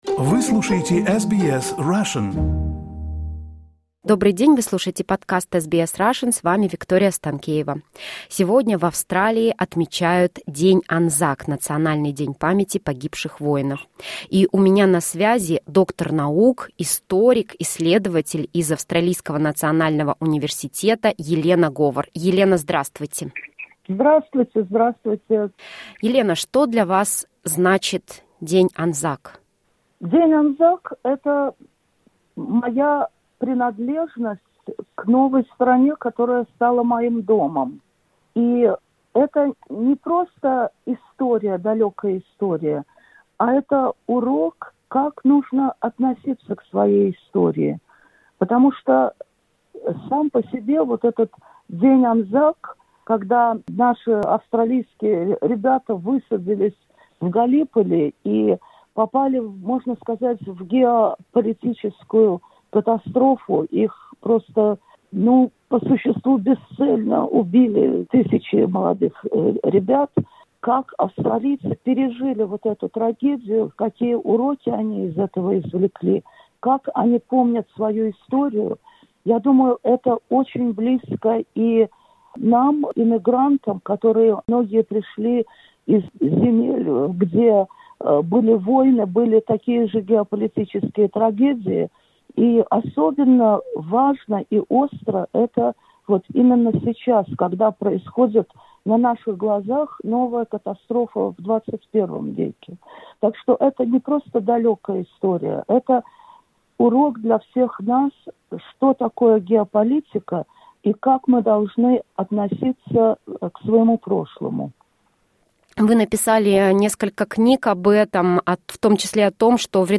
В интервью она рассказала об историях русских, украинских и белорусских Анзаков.